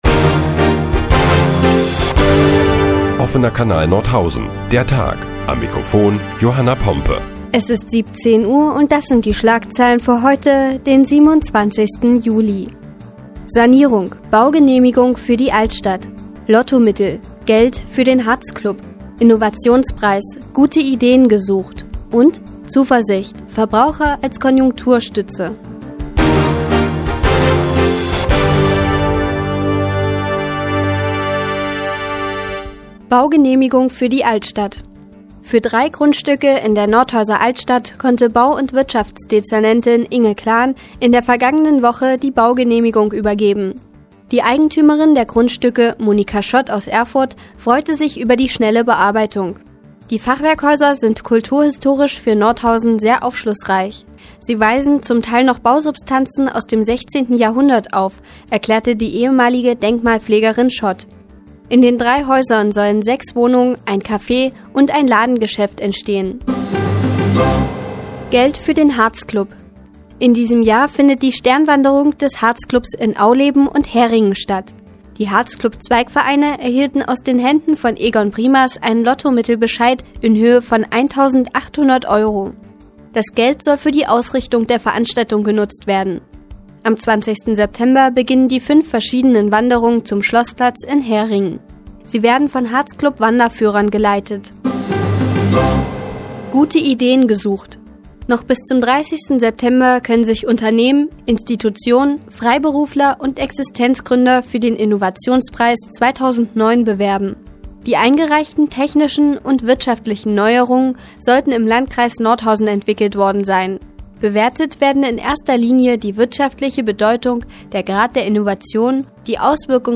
Die tägliche Nachrichtensendung des OKN ist nun auch in der nnz zu hören. Heute geht es unter anderem um Sanierungen in der Altstadt und um die Suche nach guten Ideen.